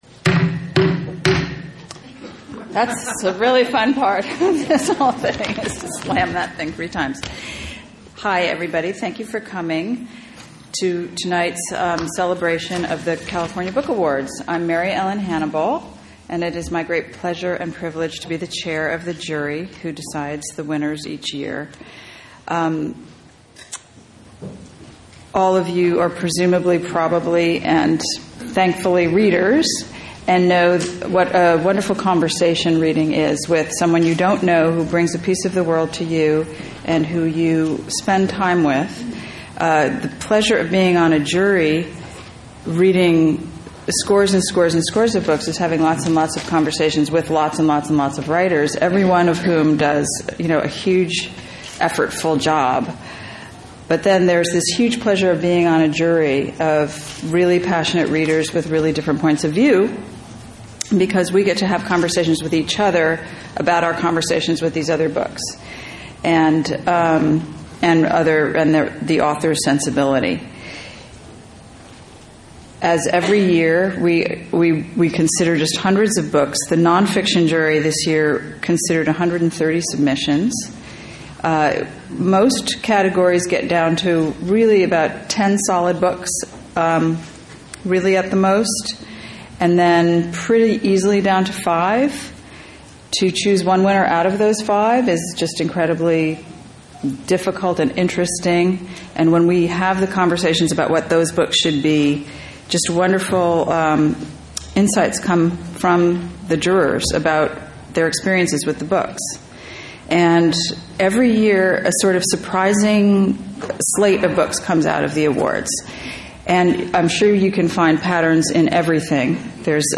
At our special awards ceremony, we will bestow gold and silver medals in several categories, including: fiction, nonfiction, first fiction, poetry, young adult, juvenile, Californiana and contribution to publishing. Hear from some literary giants and amazing writers.